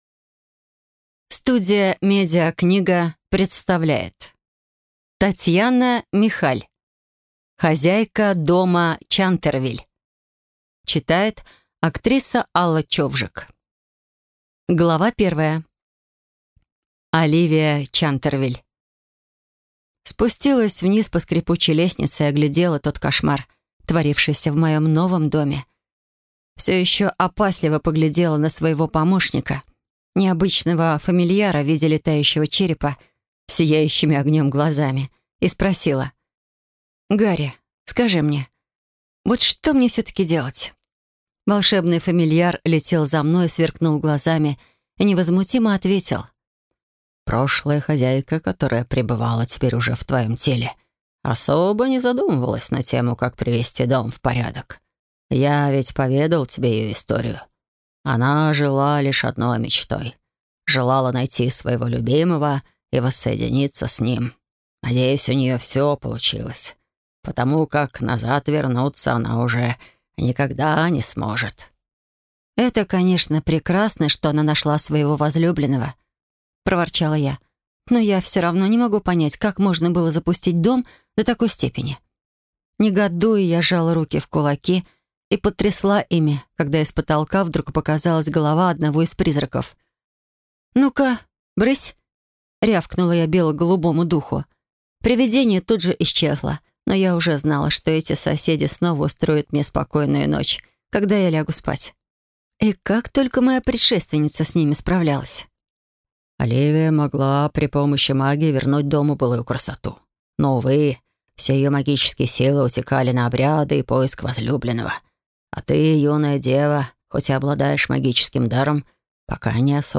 Аудиокнига Хозяйка дома Чантервиль | Библиотека аудиокниг